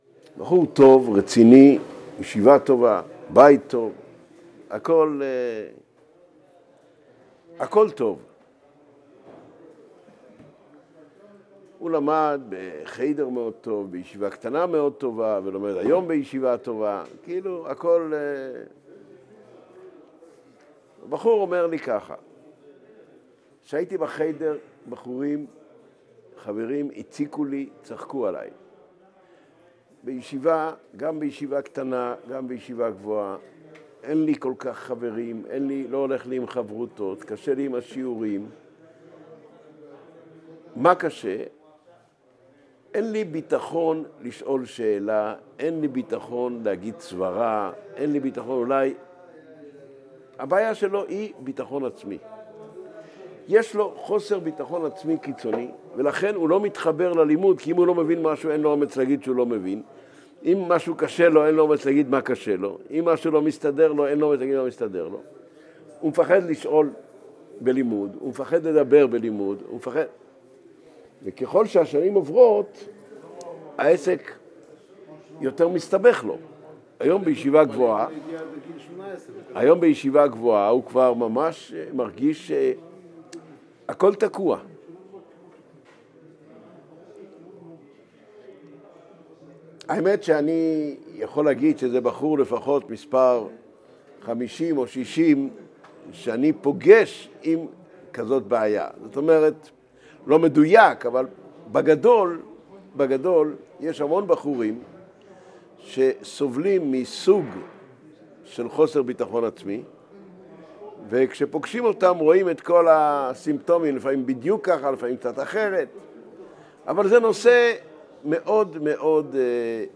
Аудио-Урок № 3.